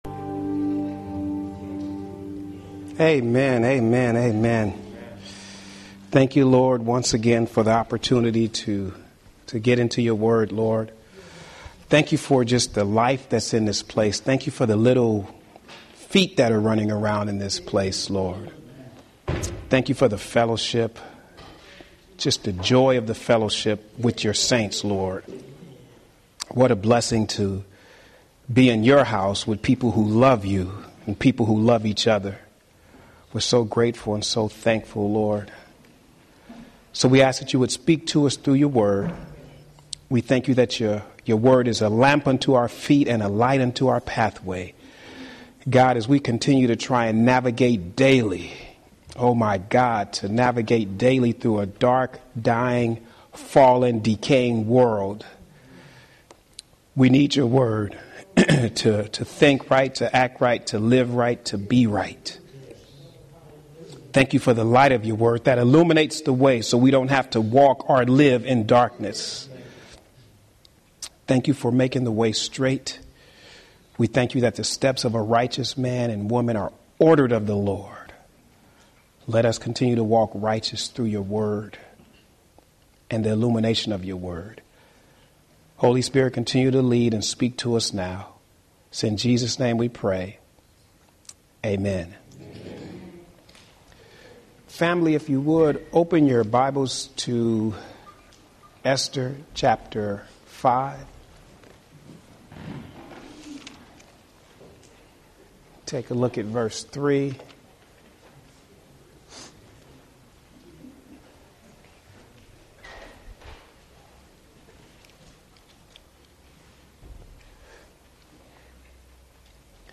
Home › Sermons › Spirit Filled and Spirit Sealed – Esther and Solomon